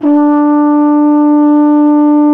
TENORHRN D 2.wav